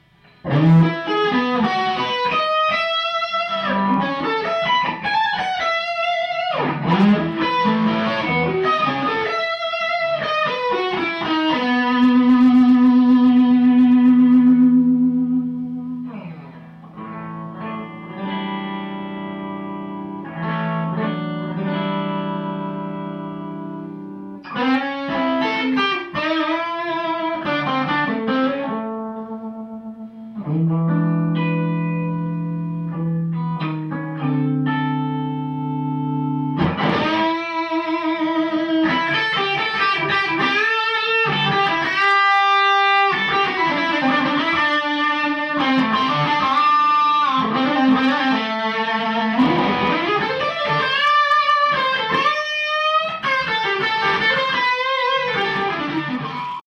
Would something like this (the singing character) be possible with a Rocket (especially at 37s to the end) or is it just too much gain?